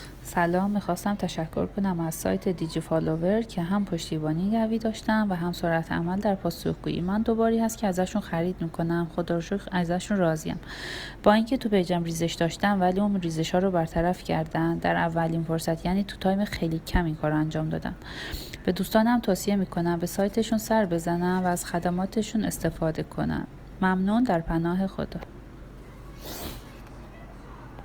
نظرات مشتریان عزیزمون با صدای خودشون